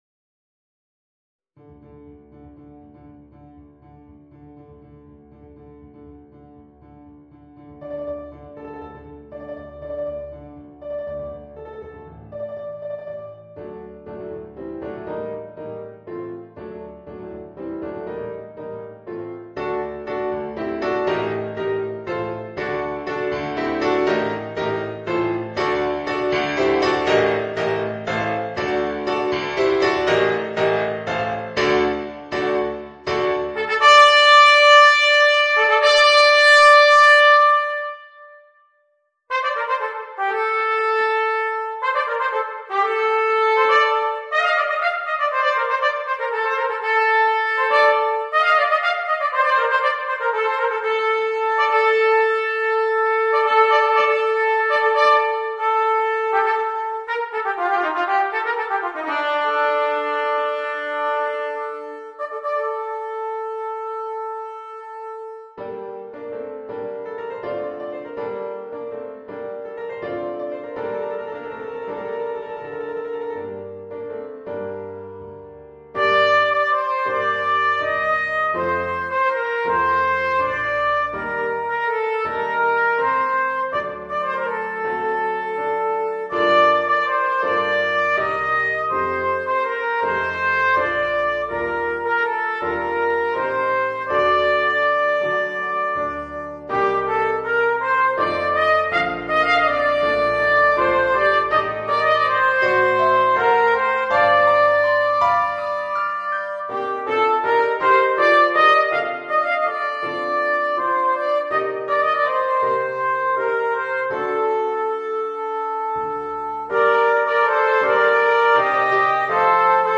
Für 2 Trompeten und Klavier